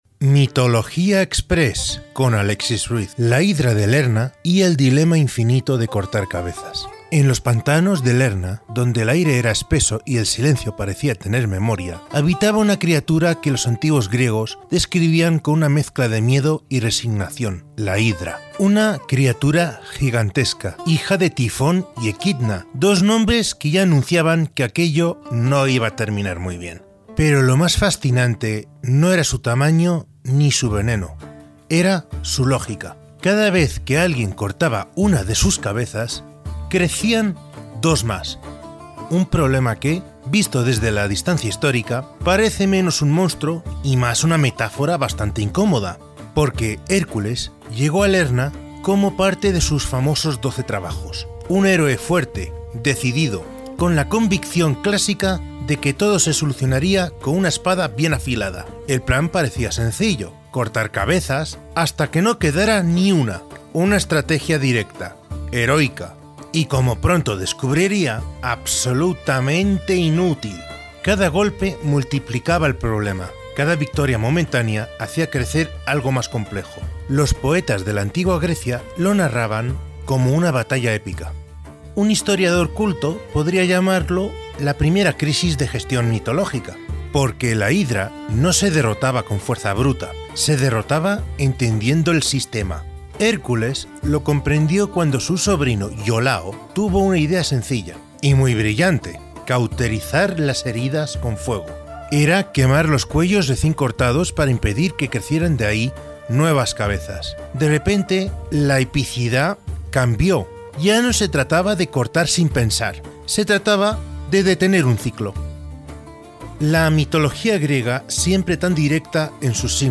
A través de una narración pausada y envolvente, el relato combina historia, simbolismo y pensamiento crítico, mostrando que la verdadera victoria no llegó solo por la fuerza del héroe, sino por la inteligencia compartida y el cambio de enfoque.